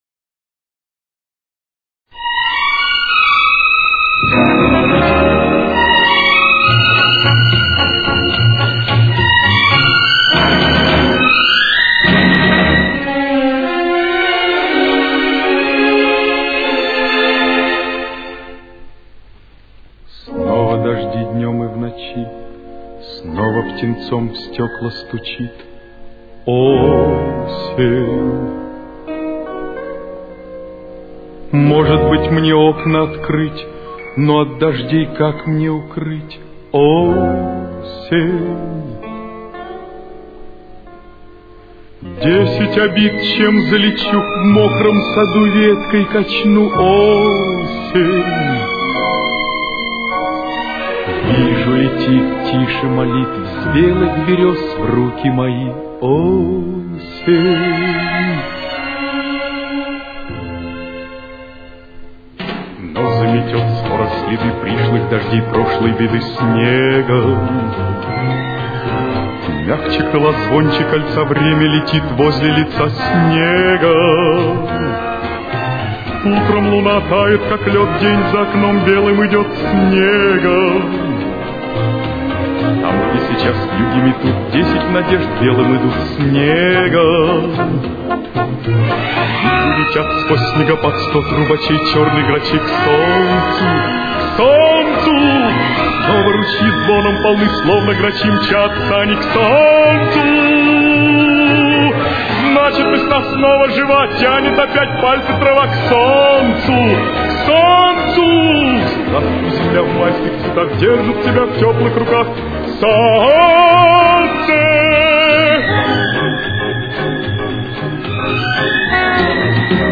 с очень низким качеством (16 – 32 кБит/с)
Си-бемоль минор. Темп: 220.